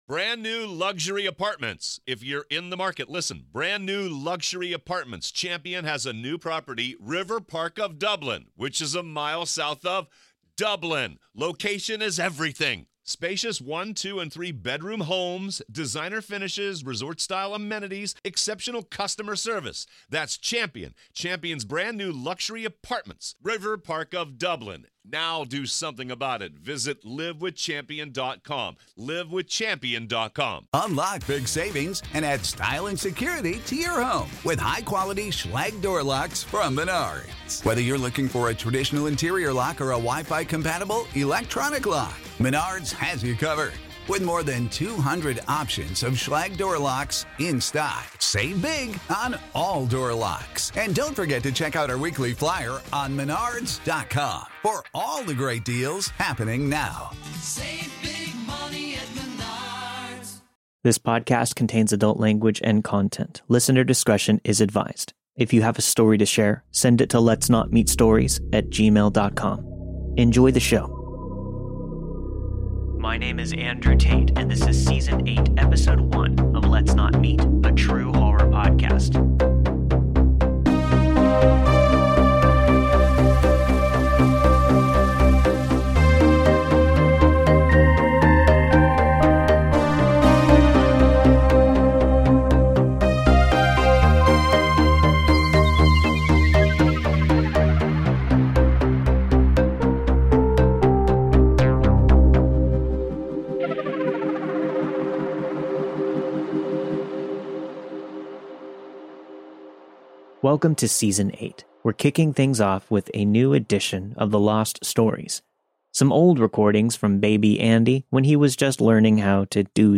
All of the stories you've heard this week were narrated and produced with the permission of their respective authors.